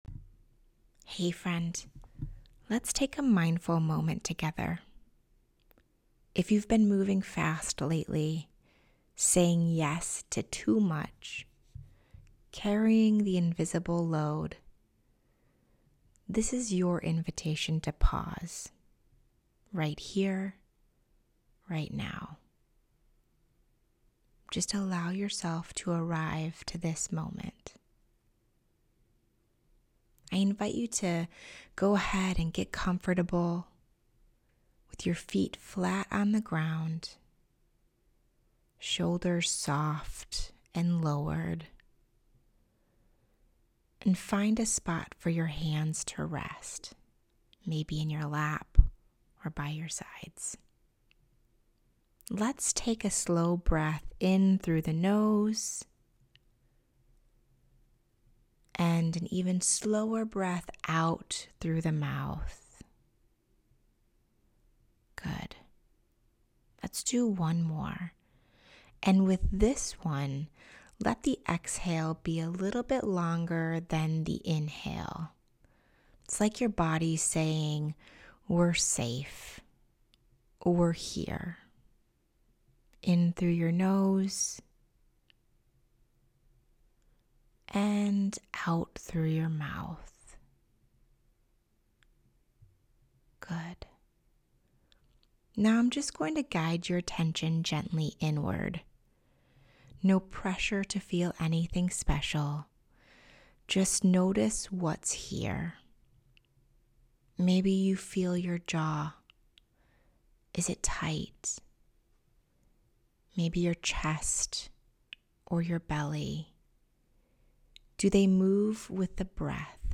So instead of adding to the noise, I made something small for you: A four-minute guided meditation to help you choose calm, gratitude, and your next useful thought.
Calm-Guided-Meditation-for-Newsletter.m4a